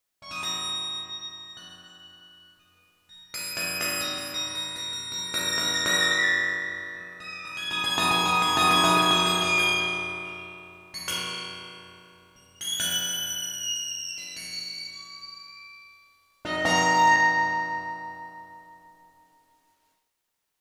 Инструмент — Waterphn (синтезированный).
А: Обращает на себя внимание высокий уровень шума у S-YXG100.